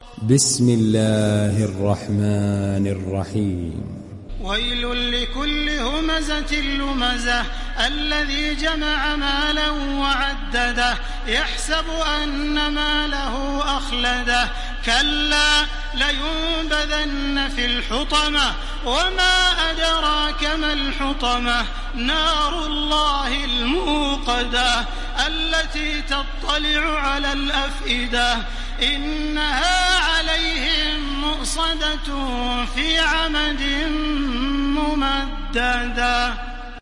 ডাউনলোড সূরা আল-হুমাযাহ্ Taraweeh Makkah 1430